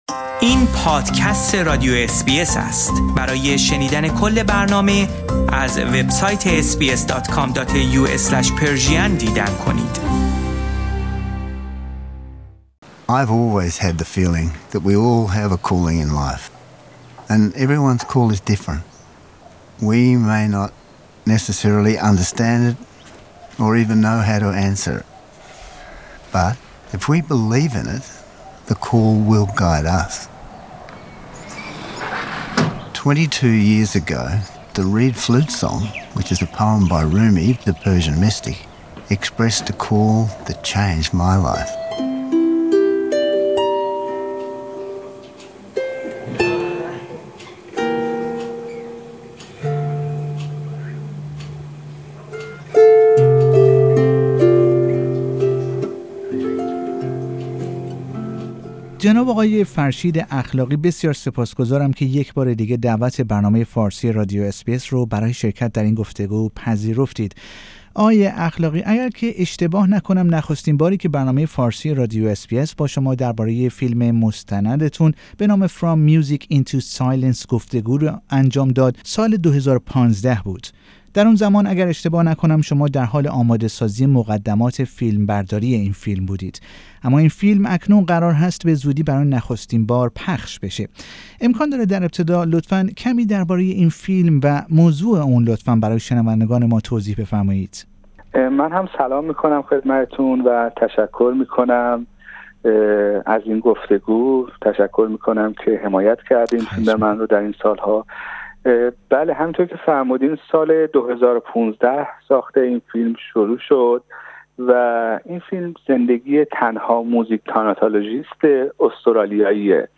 “From Music into Silence” documentary is the story of a man who changed his life to offer peace and calmness to people.